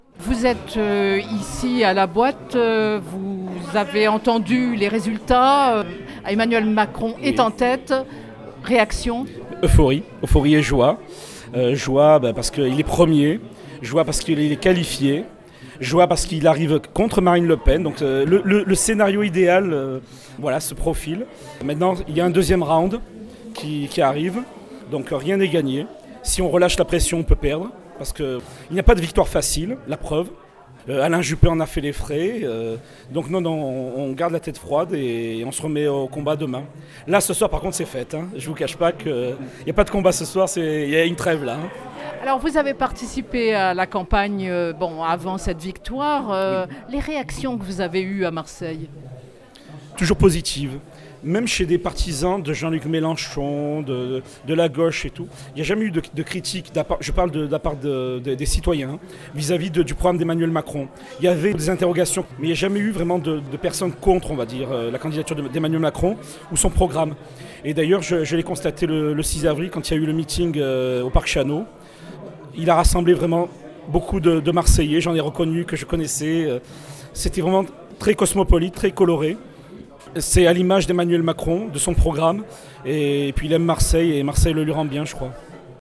Marseille – Au QG d’En Marche la victoire est savourée: réactions